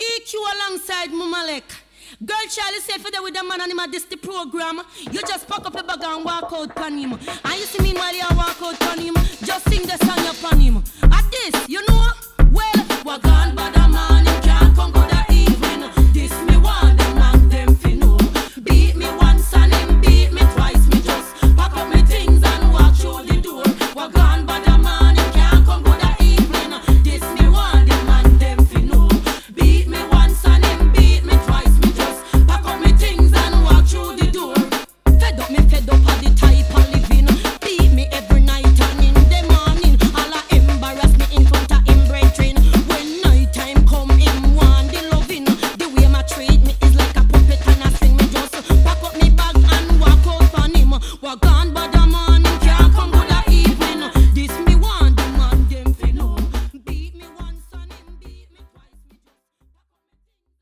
play mp3 clip   Dancehall with a junglistic edge.
harmony vocalists